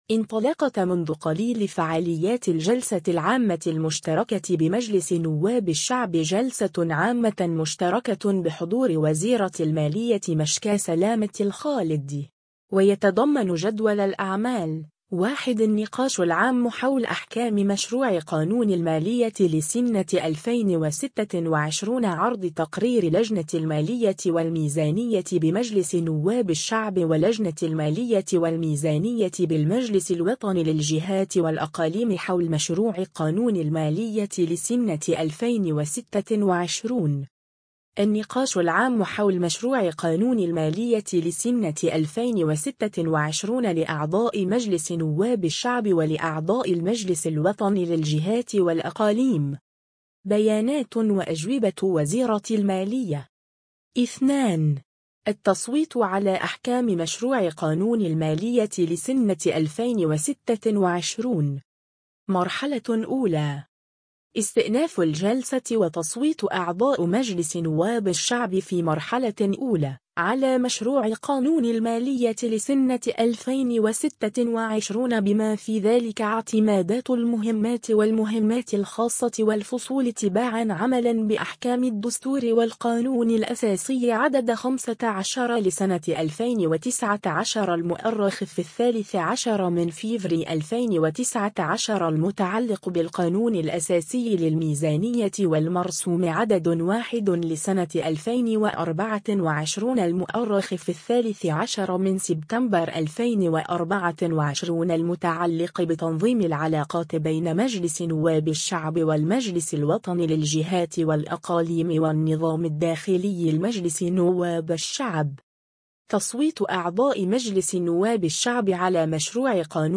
إنطلقت منذ قليل فعاليات الجلسة العامة المشتركة بمجلس نواب الشعب جلسة عامة مشتركة بحضور وزيرة المالية مشكاة سلامة الخالدي.